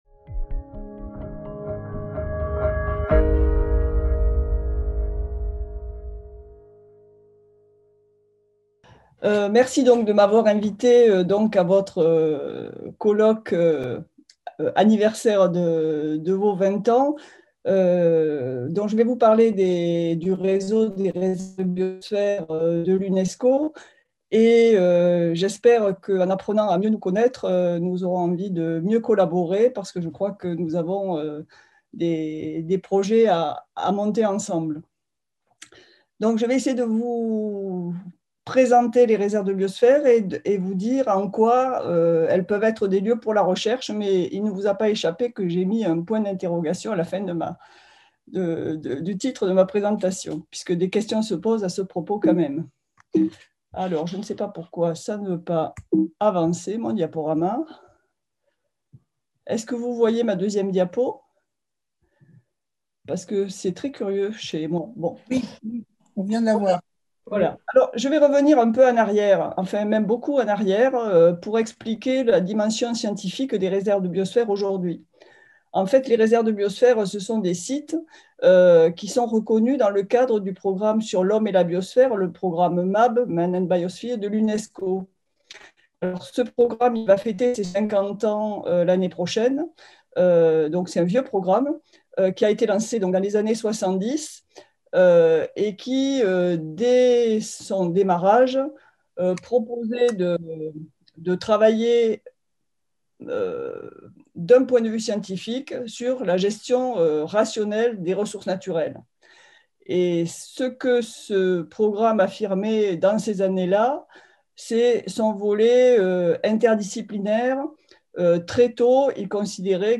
Conférence plénière - Réserves de biosphère, des lieux pour la recherche ?